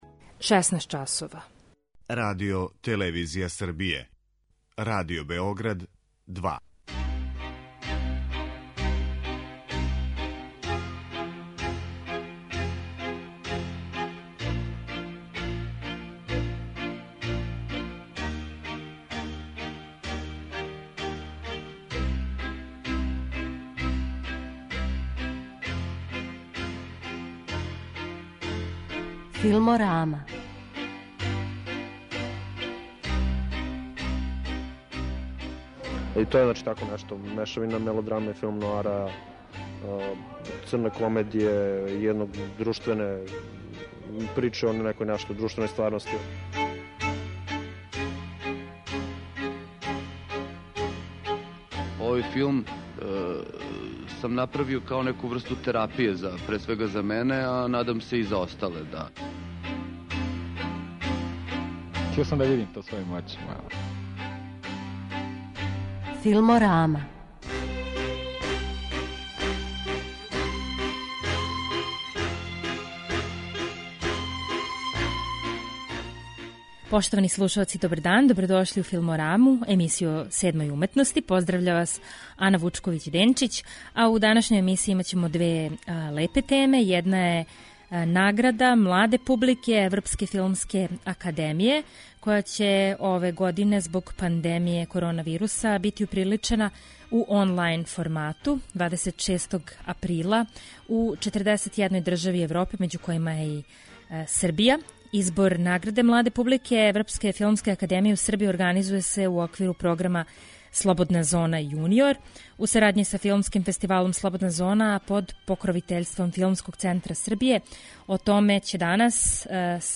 Емисија о седмој уметности